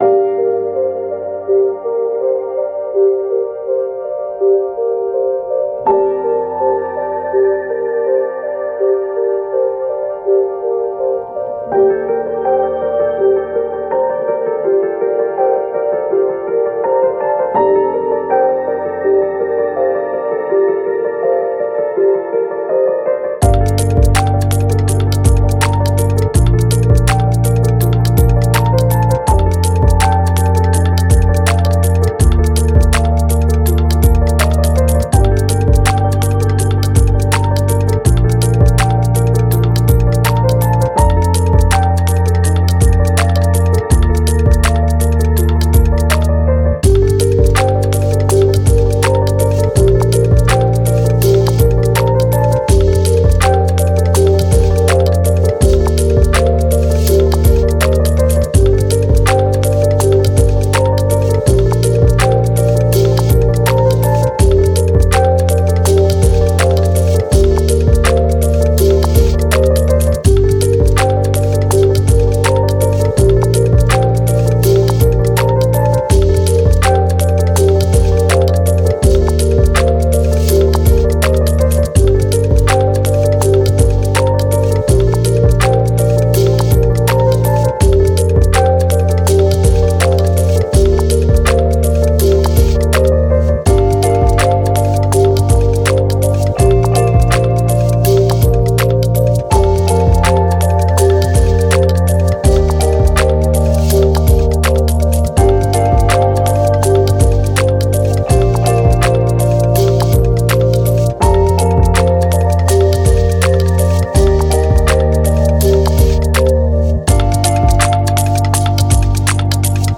Ambient, Downtempo, Thoughtful, IDM